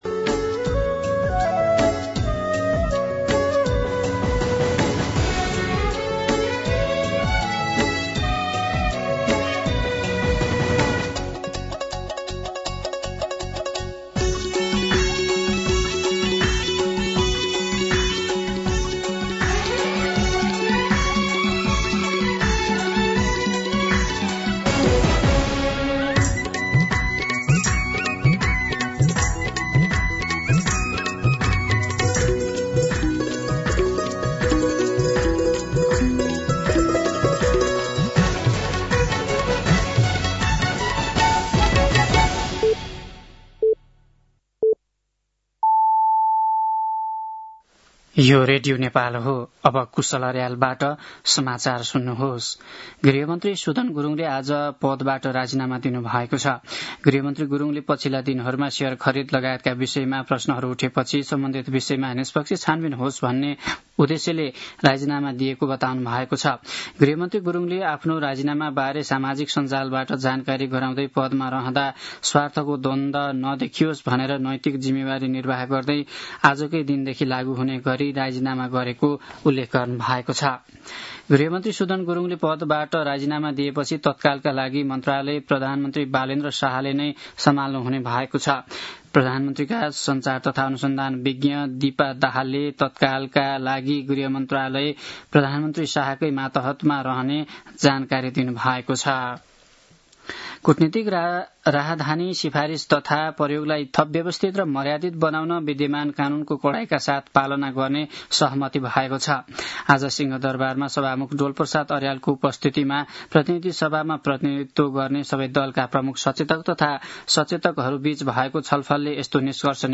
दिउँसो ४ बजेको नेपाली समाचार : ९ वैशाख , २०८३
4pm-News-09.mp3